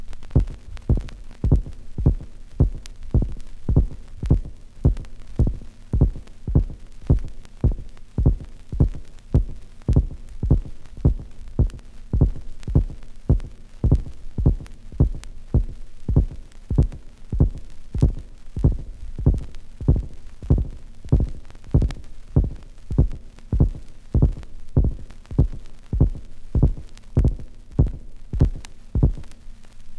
• robot - monster heartbeat - vinyl sample.wav
Recorded from Sound Effects - Death and Horror rare BBC records and tapes vinyl, vol. 13, 1977.
robot_-_monster_heartbeat_-_vinyl_sample_FN8.wav